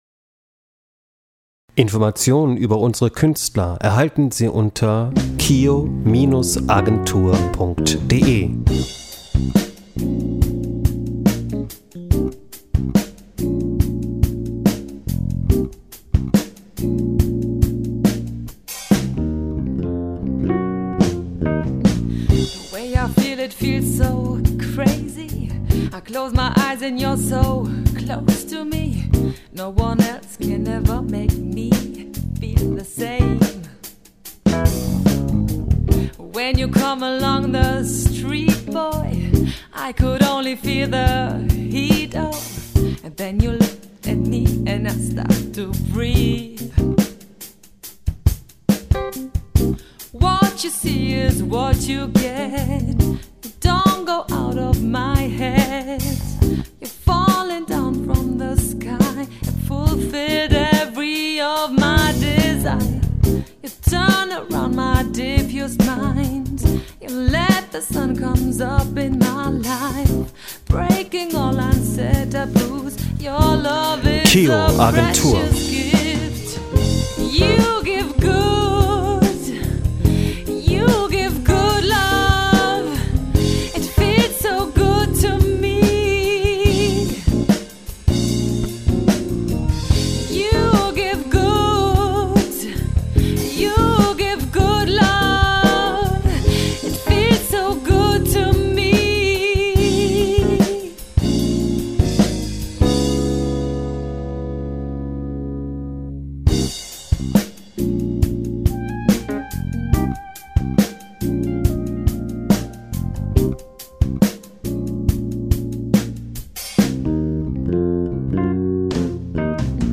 Groove-orientierte Musik, die unter die Haut geht!
mit Funk und Soul gewürzte Eigenkompositionen